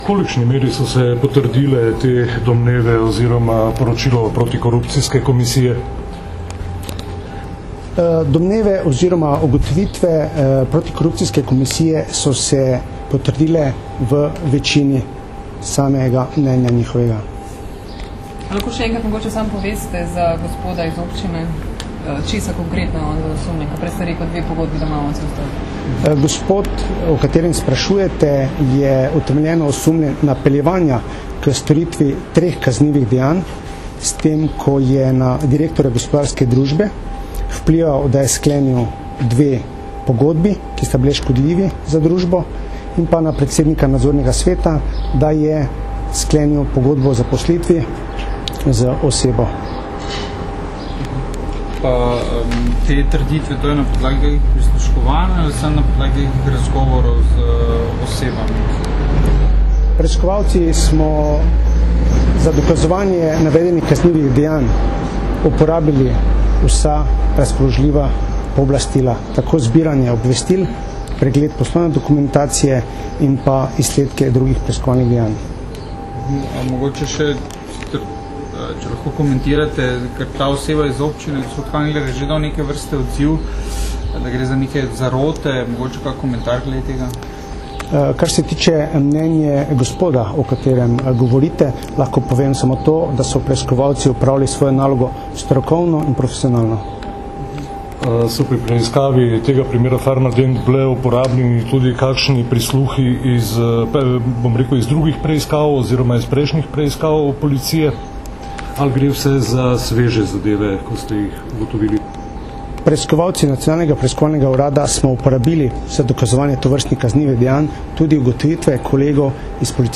Novinarska vprašanja in odgovori (mp3)